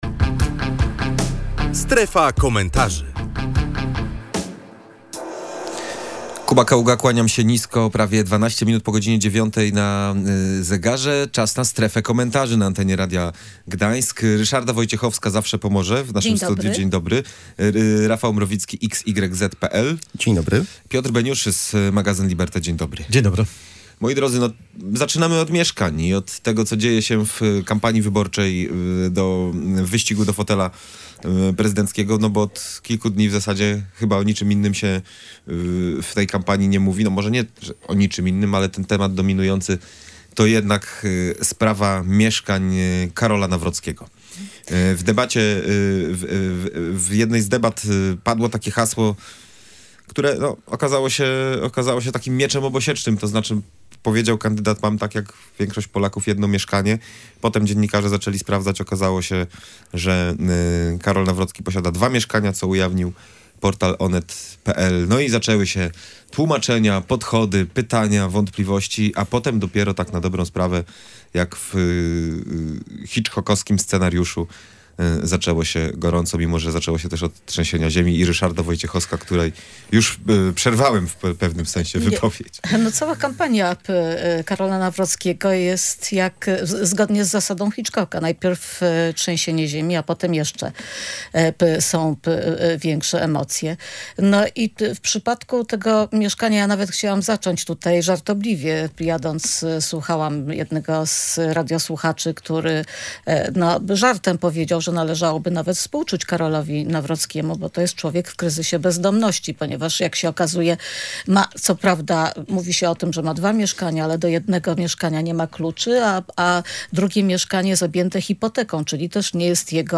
Na ten temat rozmawialiśmy w „Strefie Komentarzy”.